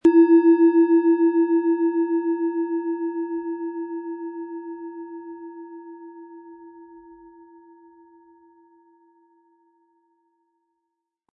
Hopi Herzton
Nach uralter Tradition von Hand getriebene Planetenklangschale Hopi-Herzton.
Der Schlegel lässt die Schale harmonisch und angenehm tönen.
Das Klangbeispiel lässt Sie den Originalton der Planetenschale hören.